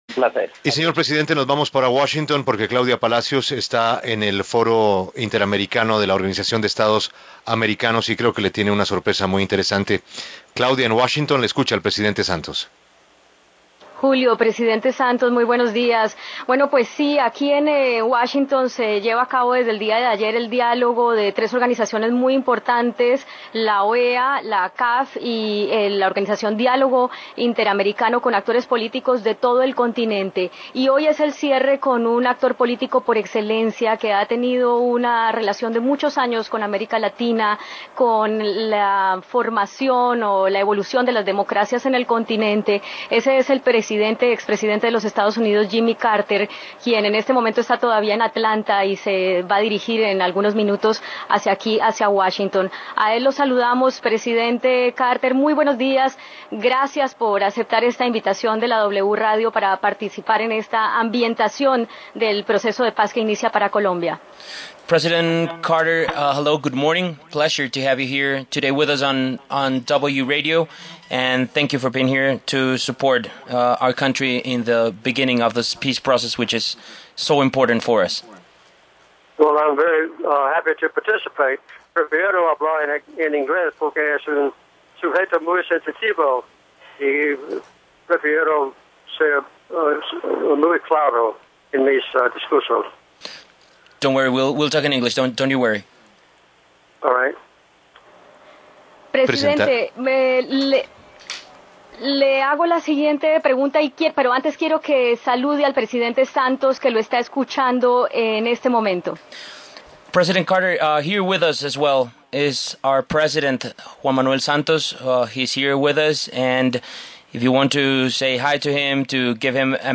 W Radio habló con Jimmy Carter